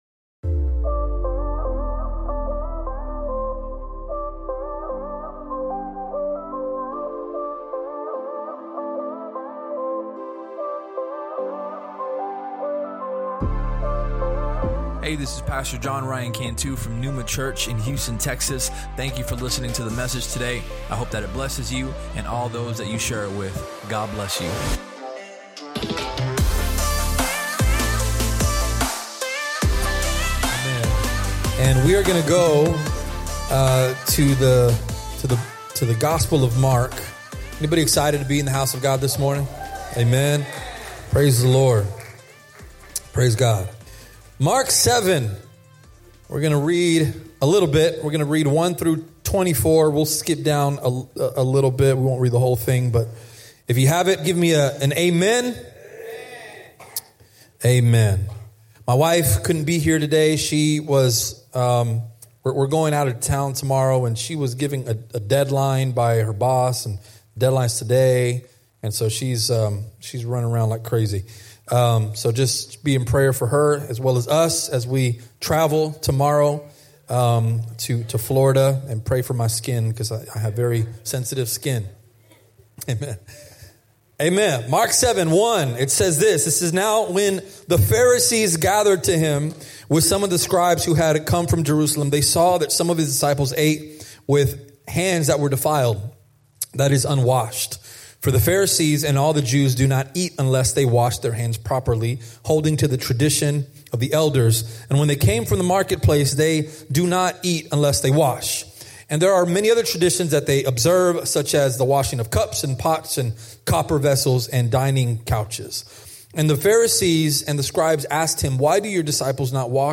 Sermon Topics: Worship, Heart, Tradition If you enjoyed the podca